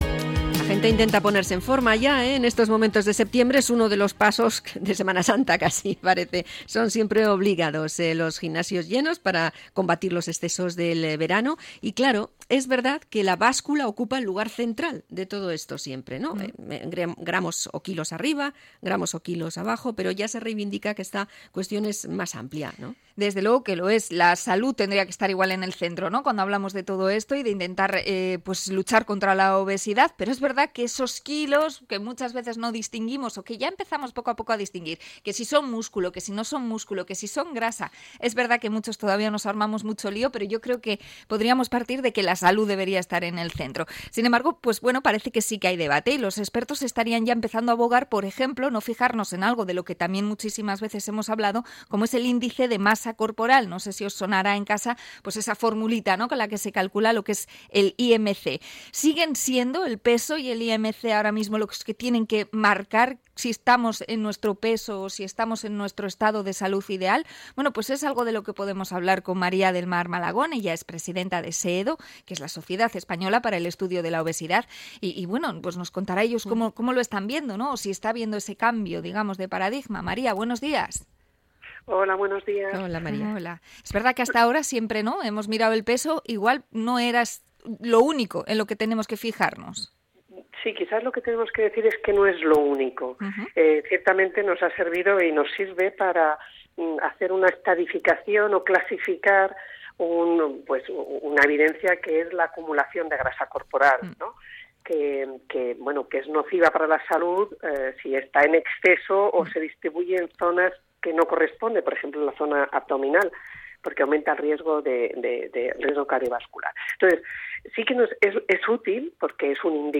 Entrevista a SEEDO sobre los sanos gordos